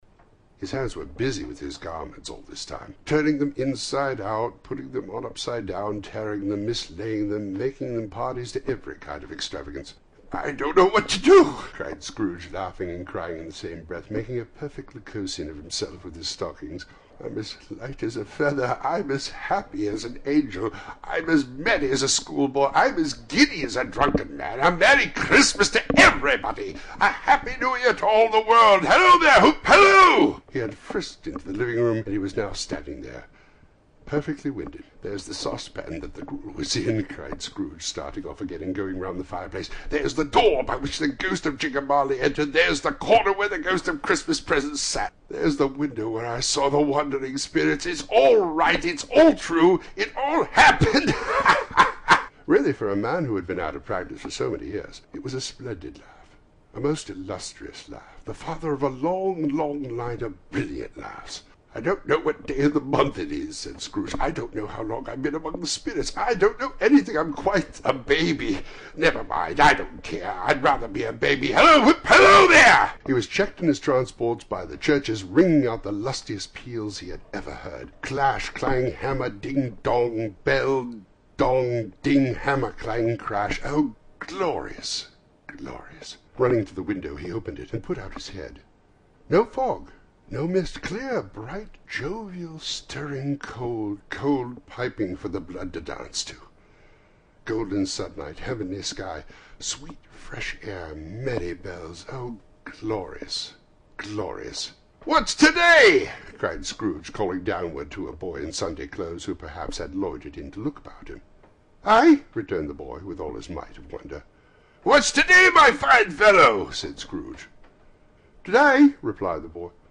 A Christmas Carol by Charles Dickens | Original And Classic Audiobook